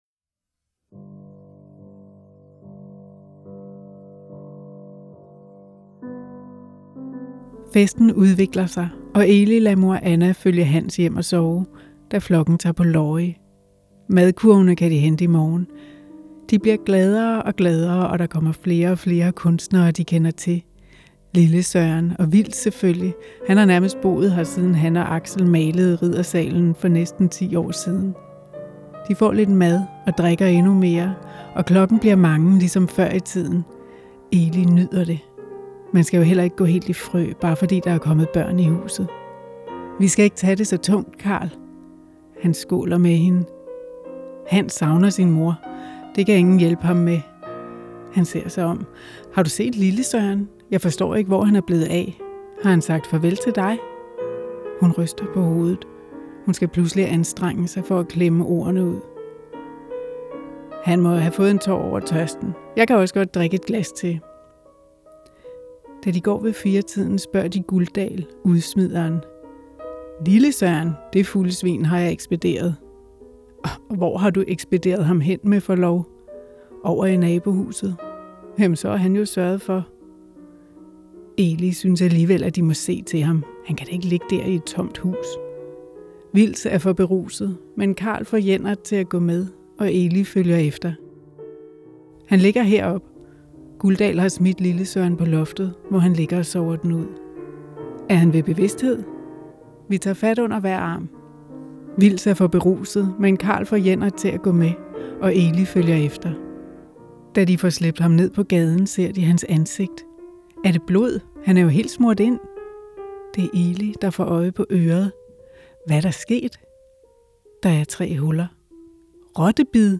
læse et uddrag om forlystelsesetablissementet i Allegade.